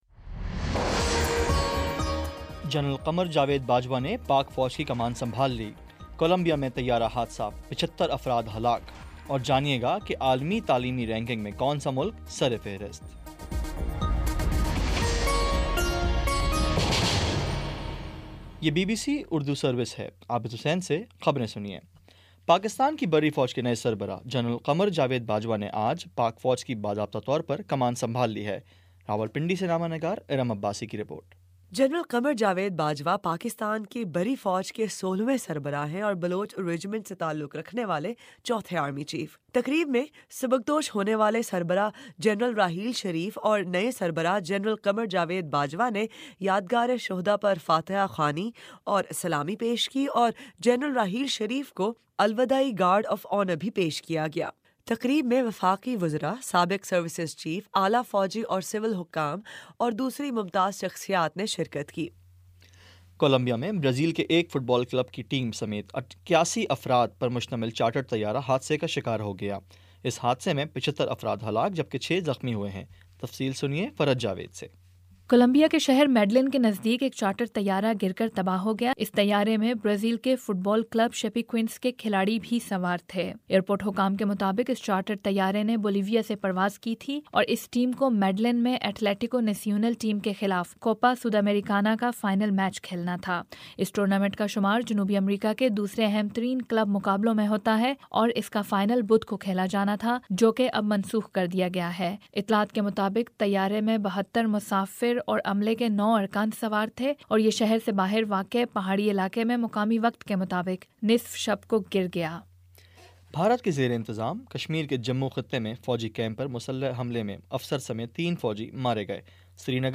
نومبر 29 : شام چھ بجے کا نیوز بُلیٹن